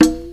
OZ-Perc (Love).wav